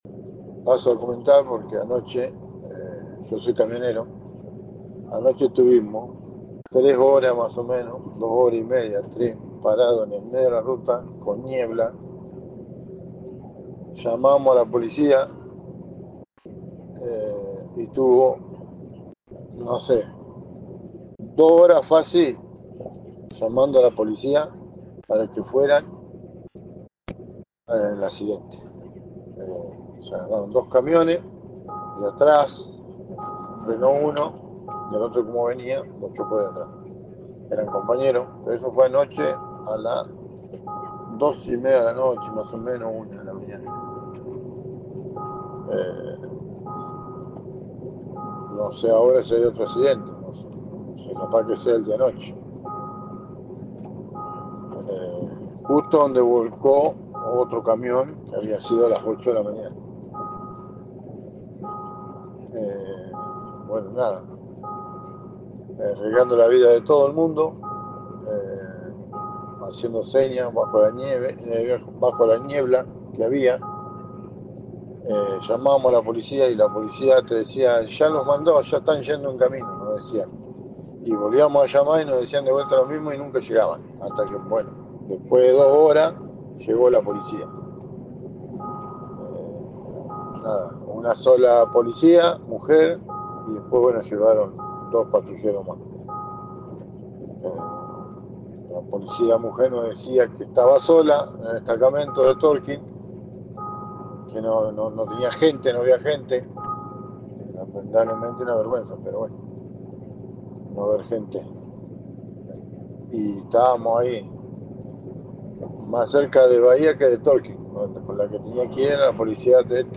EL TESTIMONIO DE UN CAMIONERO A DE LA BAHÍA
TESTIMONIO-CHOQUE.mp3